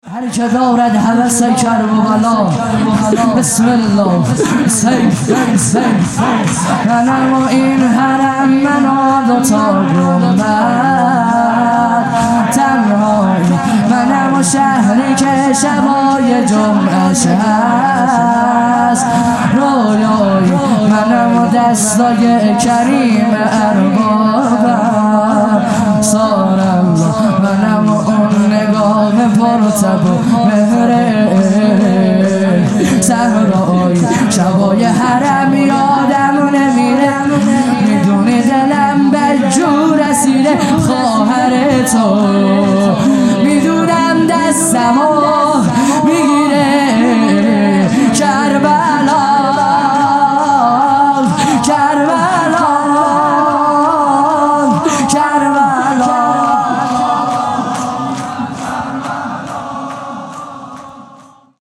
خیمه گاه - هیئت بچه های فاطمه (س) - شور | من این حرم منو دوتا گنبد
محرم ۱۴۴۱ | شب هفتم